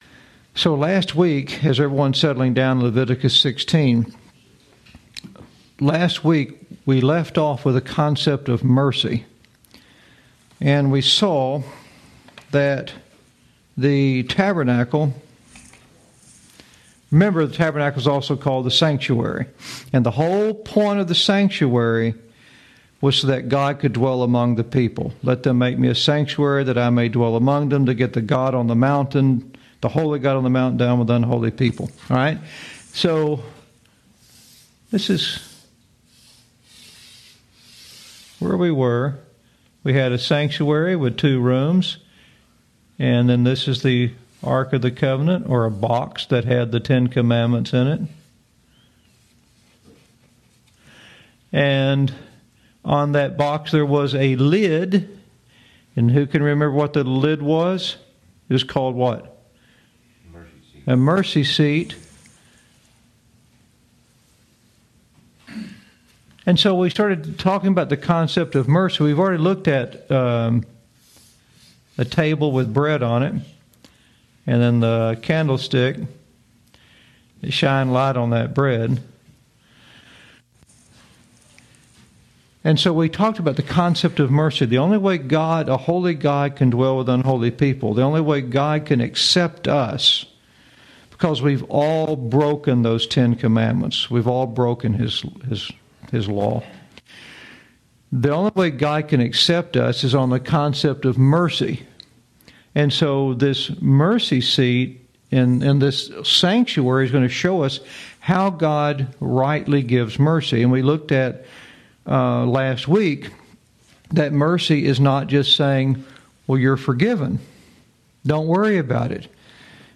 Lesson 25